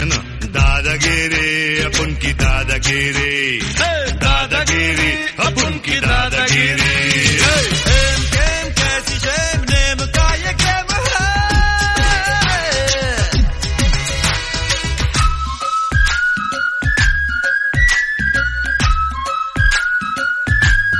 energetic ringtonemass ringtonetrending ringtone